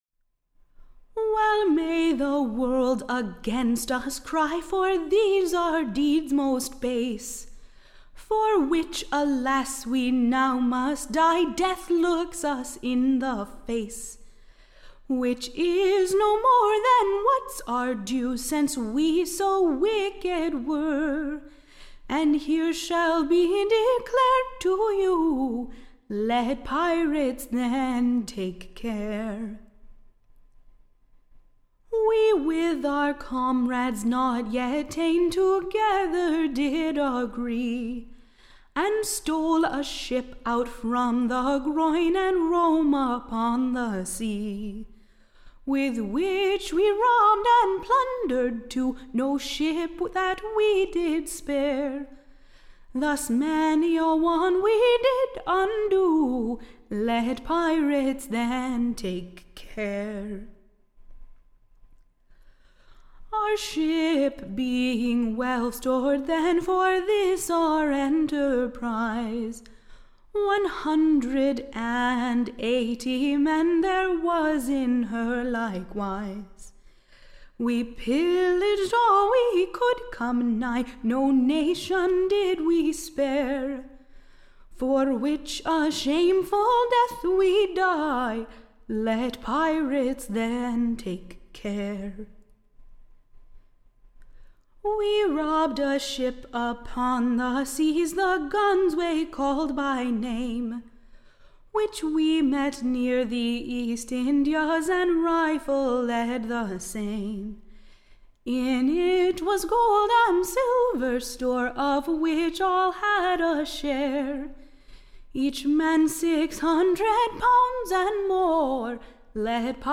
Recording Information Ballad Title Villany Rewarded; / OR, THE / PIRATES Last Farewel / To the VVorld: / Who was Executed at Execution Dock, on Wednesday the 25th. of November, 1696.